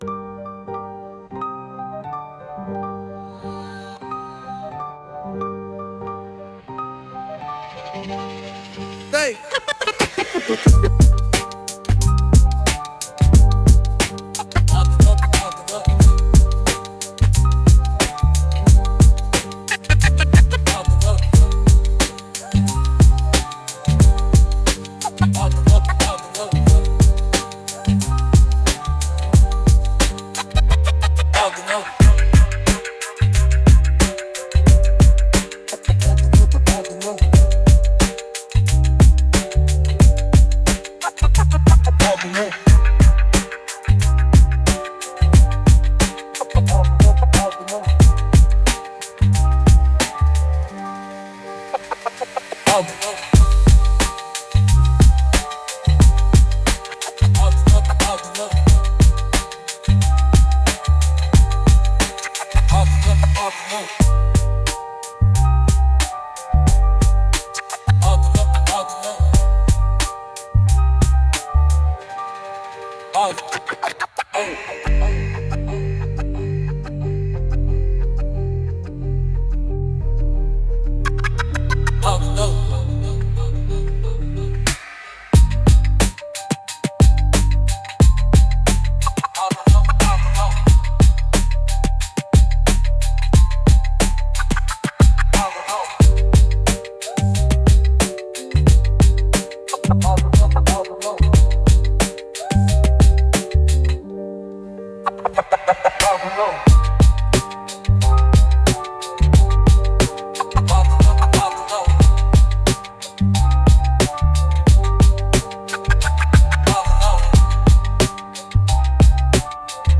Instrumental - RUN from Those who Censor - Real Liberty Media dot XYZ